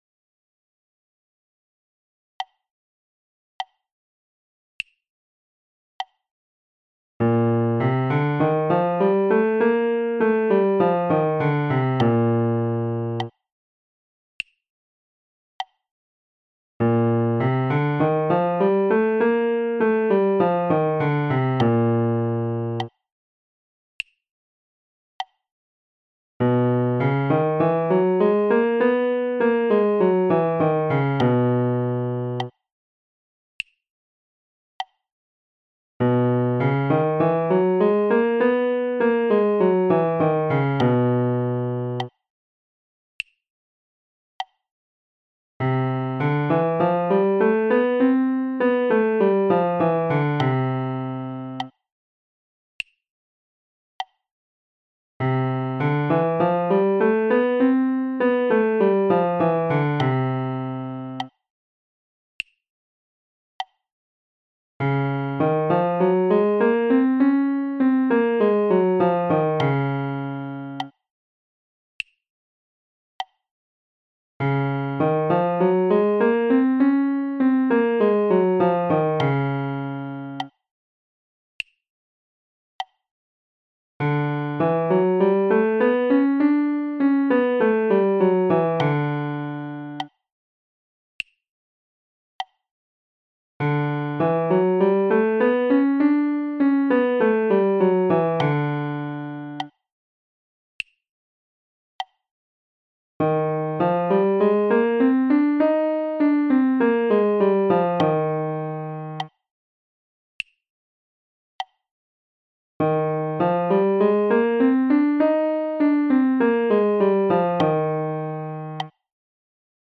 HIGH REGISTER DEVELOPMENT
Keep the same relaxed feeling throughout the exercise. The dynamic should be kept at mezzopiano at all times regardless of register.